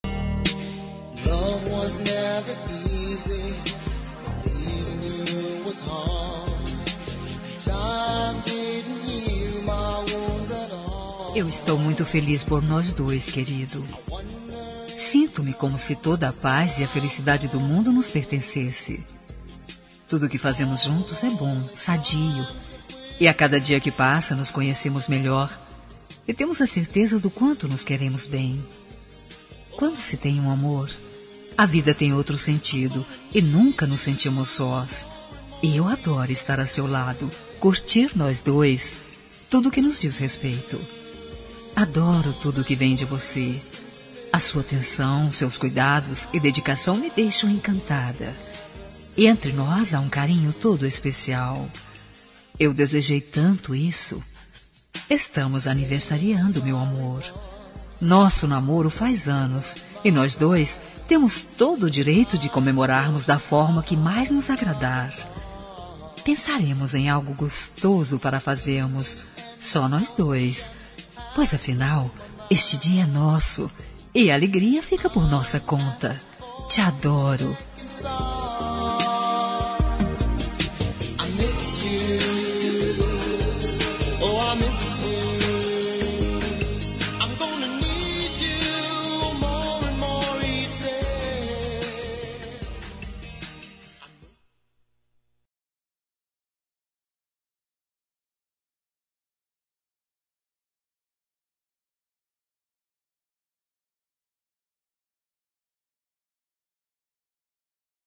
Tempo de Namoro – Voz Feminina – Cód: 710